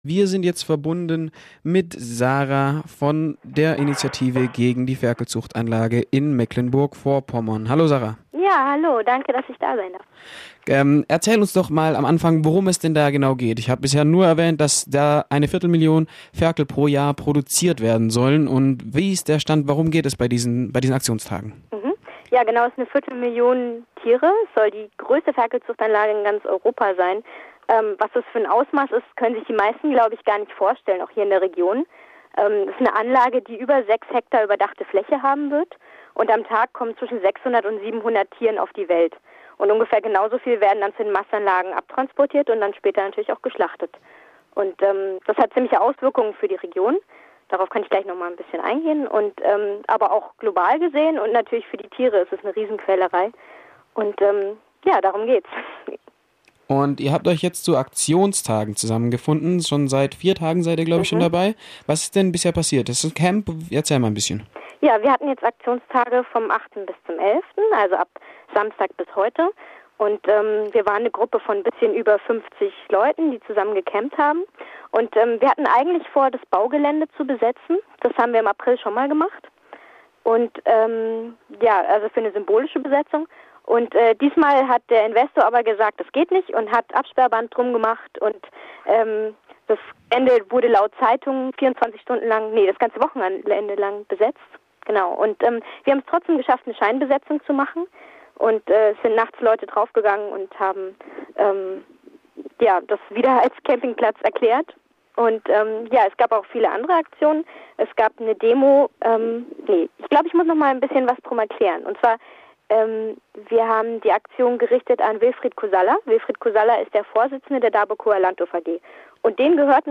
Wir haben uns mit einer AKtivistin von den AKtionstagen gegen den Bau einer Ferkelzuchtanlage in Mecklenburg Vorpommern über die Aktionstage unterhalten.